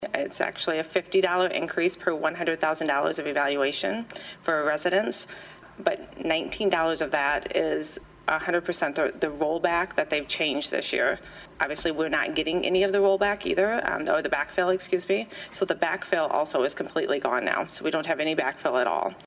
THE SIOUX CITY COUNCIL CONVENED SATURDAY TO DISCUSS THE CITY’S PROJECTED OPERATING BUDGET FOR THE NEXT FISCAL YEAR WITH INDIVIDUAL DEPARTMENT HEADS.